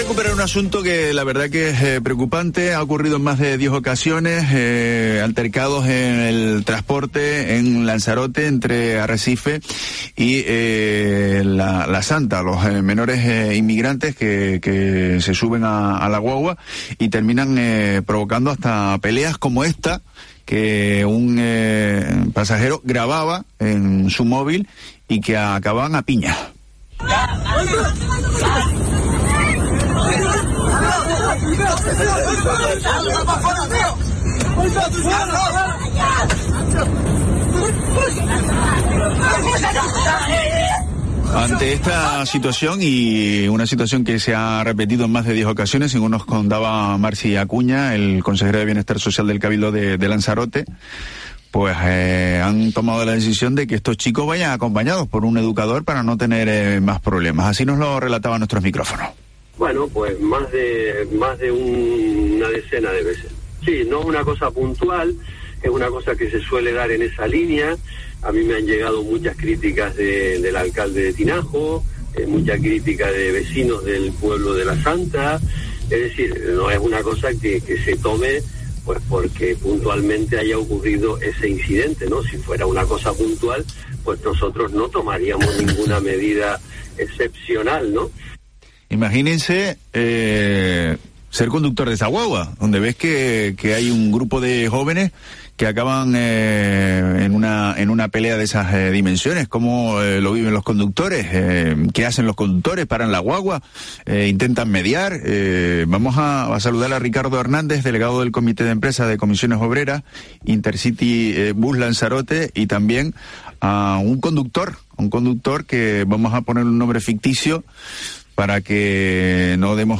En Herrera en COPE Canarias hemos hablado con uno de esos conductores que han pasado por una situación violenta mientras se encontraban de servicio.